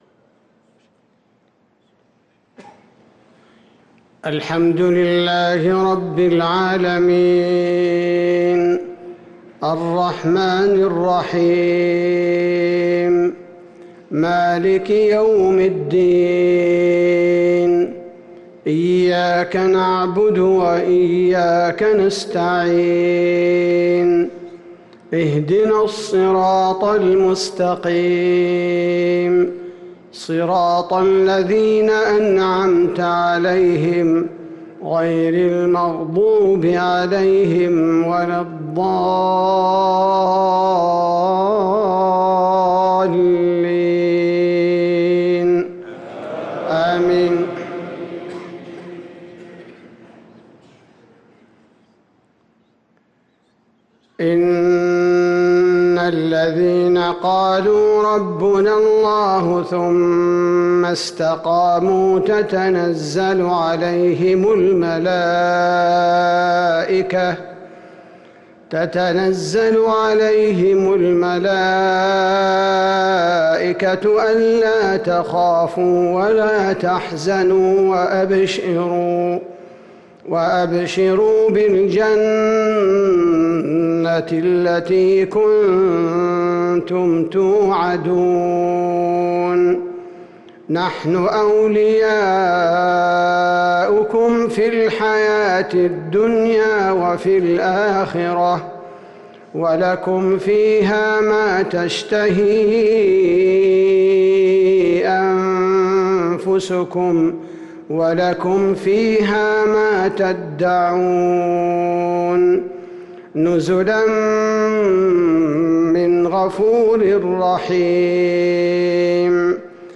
صلاة المغرب للقارئ عبدالباري الثبيتي 20 ذو القعدة 1444 هـ